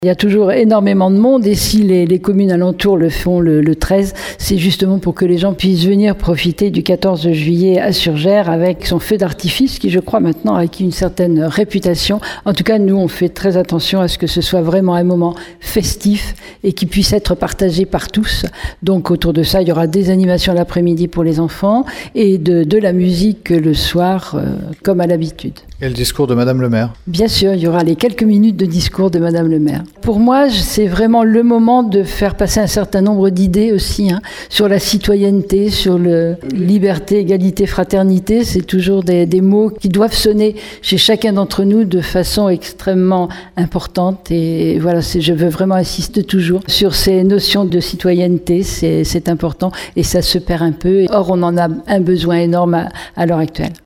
On écoute la maire de Surgères Catherine Desprez :